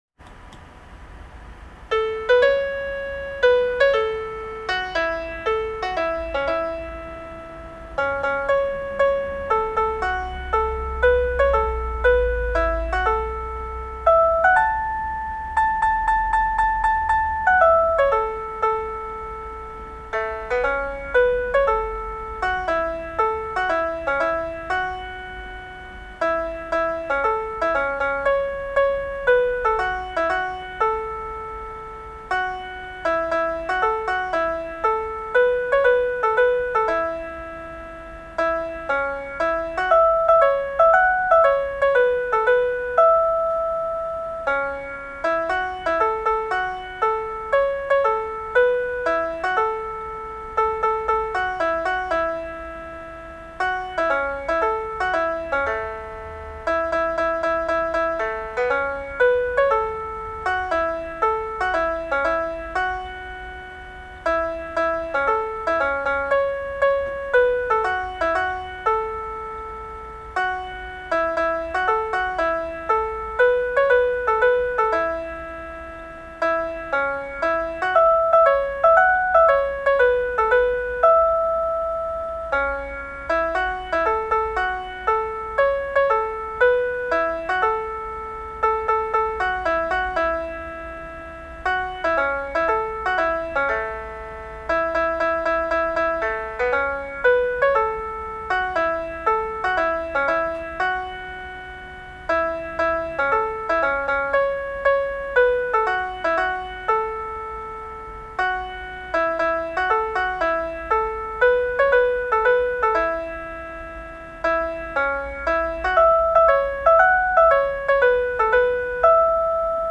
（作詞・作曲）
ｼｽﾃﾑ演奏